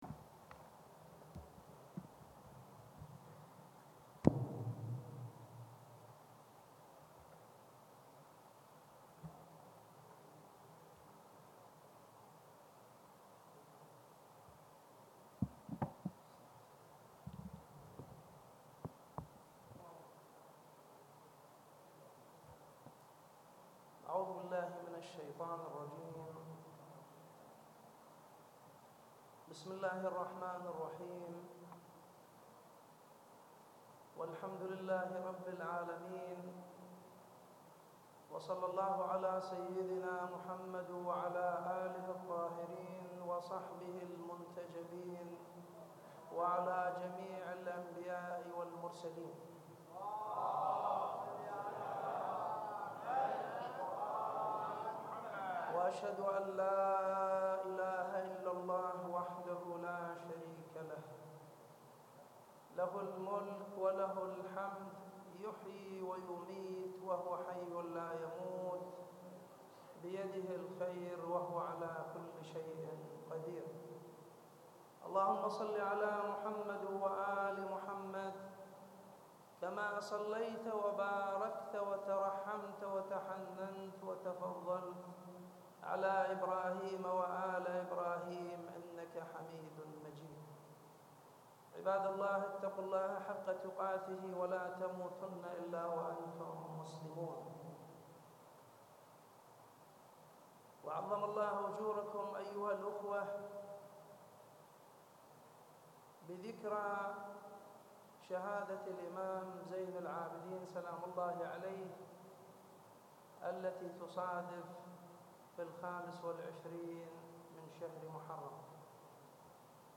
صلاة الجمعة في مدينة الناصرية - تقرير صوتي مصور -